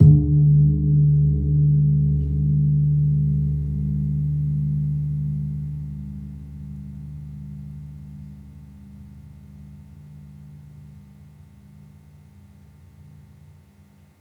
Gong-D1-f.wav